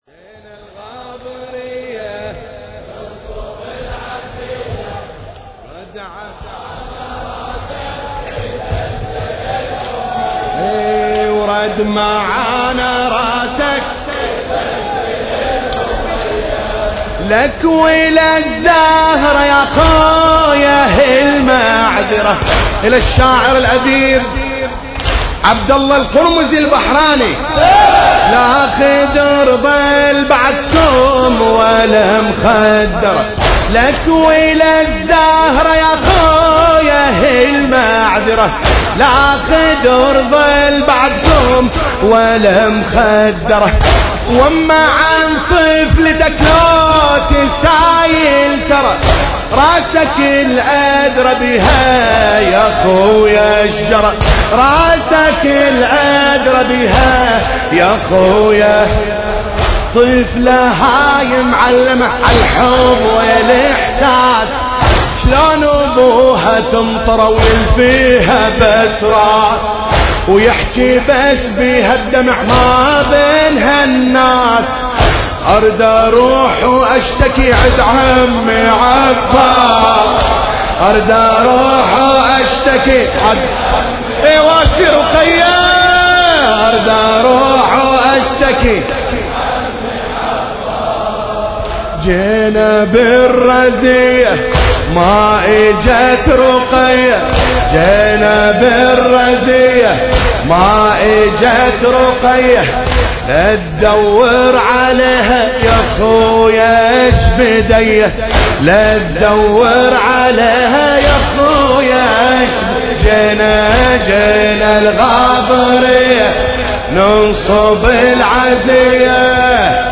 المكان: العتبة الحسينية المقدسة